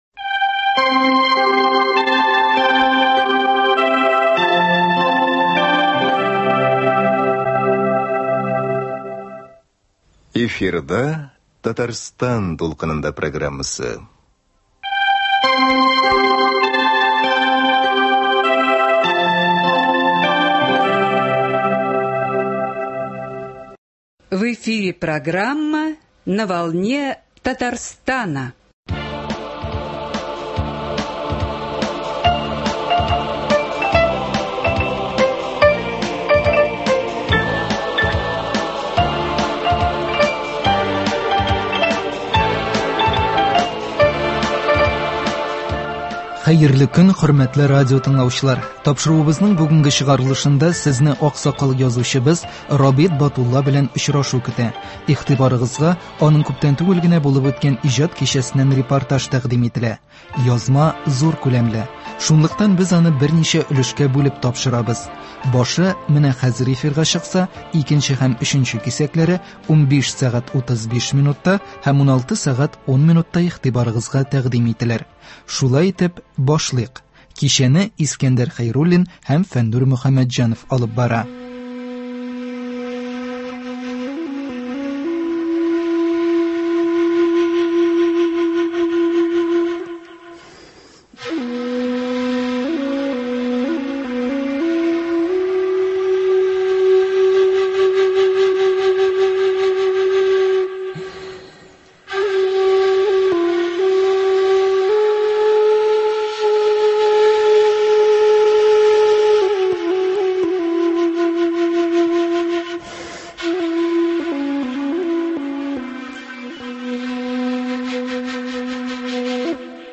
Игътибарыгызга аның күптән түгел булып үткән иҗат кичәсеннән репортаж тәкъдим ителә.